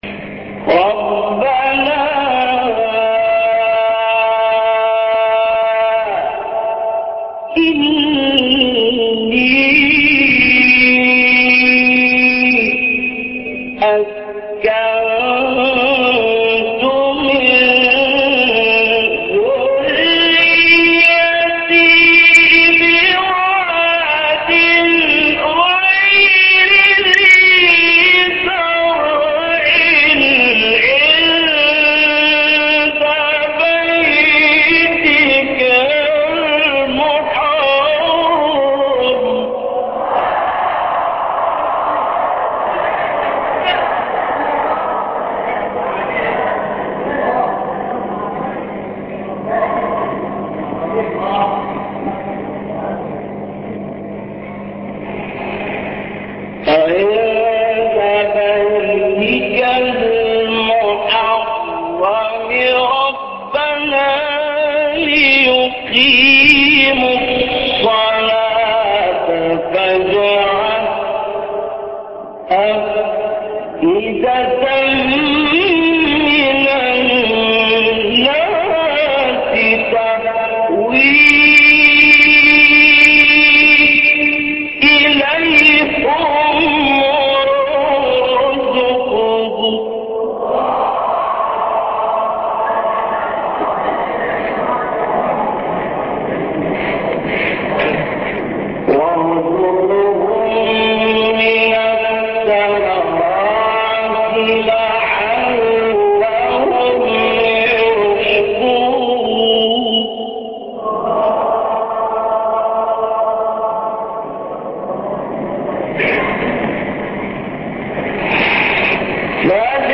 مقام الراست